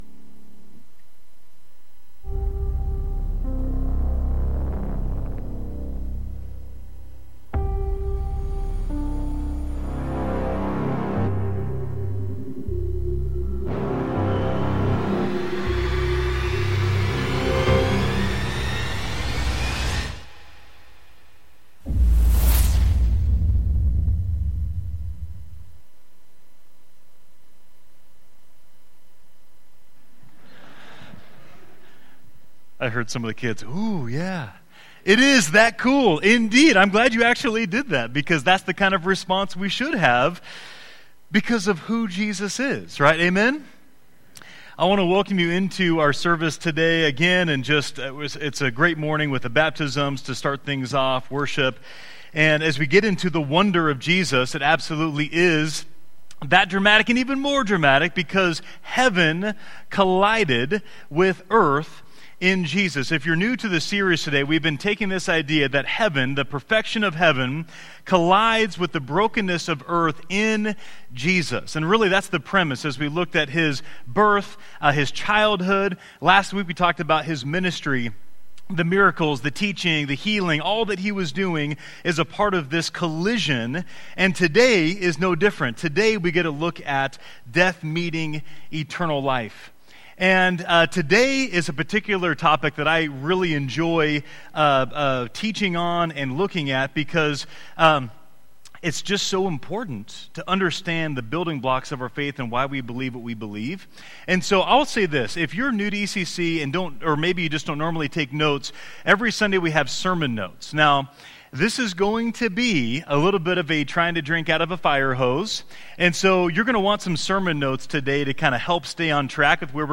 Download Download Reference Matthew 27-28 Sermon Notes 4.